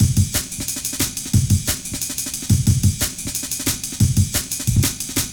amenattempt1.wav